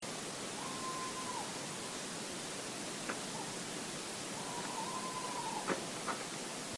Owl Midnight 16/4/14